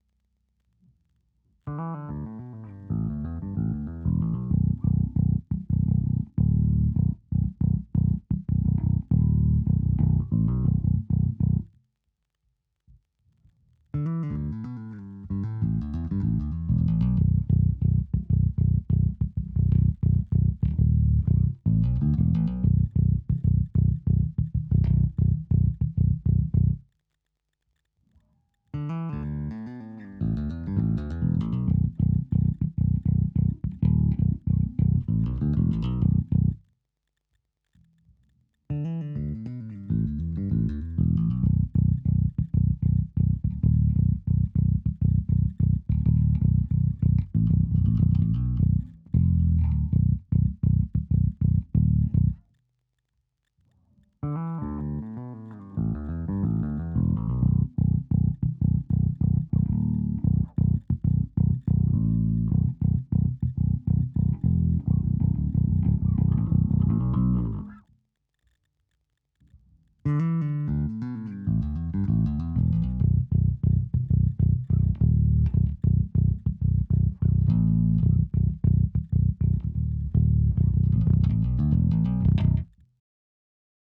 Bei der Elektronik bin ich dennoch recht verwundert, wenn auch im Positiven: Der hat nen 6-Fach Wahlschalter, wo in den hinteren beiden Positionen definitiv der Piezo drin ist.
Eine klingt wie 6 aber ohne Piezo, die andere nach Mittenboost auf dem Steg Pickup und eine die etwas mehr auf den Hals Pickup läuft.
Die lässt sich nicht bis auf Mumpf zudrehen, wie ich das kenne, aber begrenzt die Höhen schon recht drastisch wenn man sie schließt. Ich habe mich mal ganz fix durch die Presets durchgenudelt: Anhang anzeigen 878404 Anhang anzeigen 878405 Anhang anzeigen 878406 Anhang anzeigen 878407 Anhang anzeigen 878408 Edit: schöne Foddos hinzugefügt.